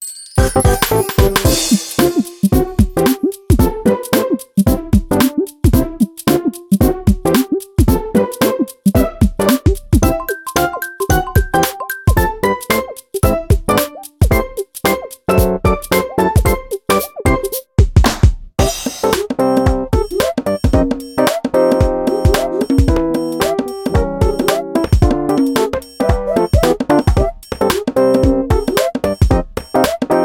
Title theme
Source Directly ripped from the ISO